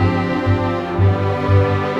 Rock-Pop 11 Strings 05.wav